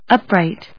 音節up・right 発音記号・読み方
/ˈʌprὰɪt(米国英語)/